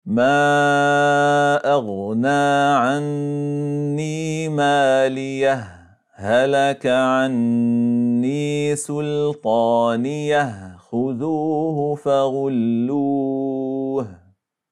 Enligt Ĥafş från ‘Aşim (في روايةِ حفصٍ عن عاصمٍ) läses det med en sukun vid både fortsättnig och stopp, som i: